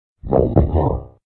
Boss_COG_VO_statement.ogg